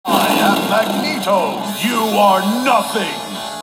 Sound Bytes from the Konami X-men Video Game